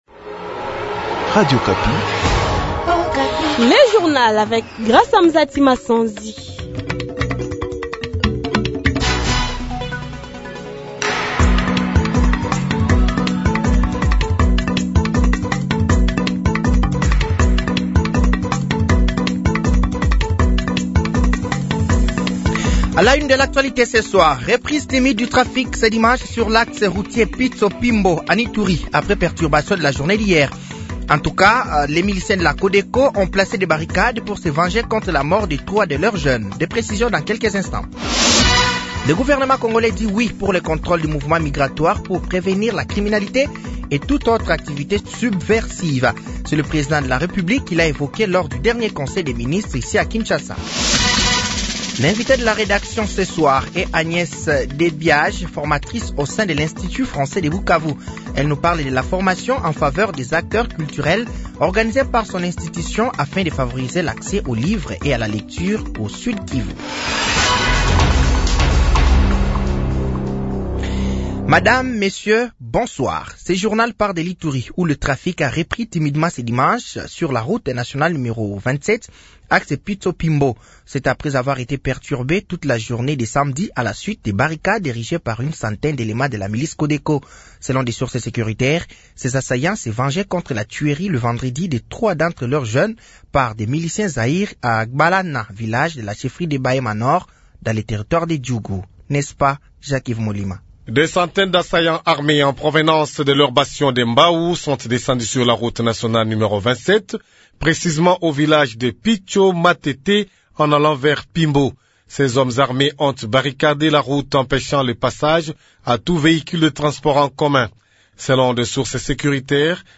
Journal francais de 18h de ce dimanche 15 septembre 2024